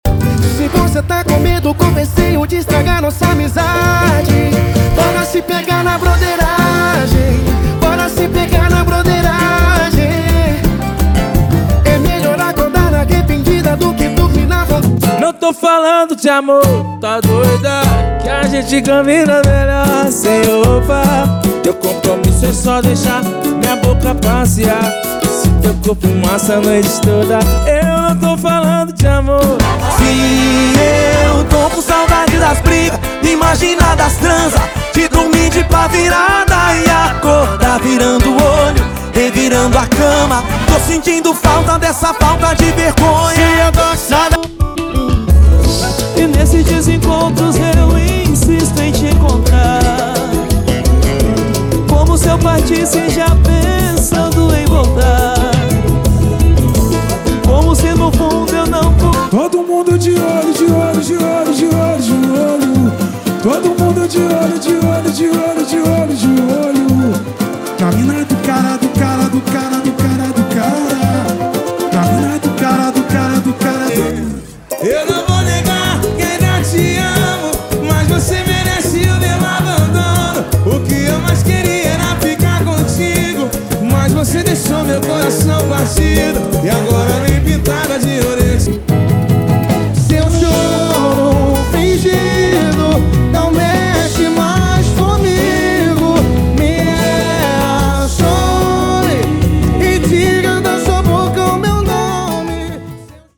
• Pagode e Samba = 50 Músicas
• Sem Vinhetas
• Em Alta Qualidade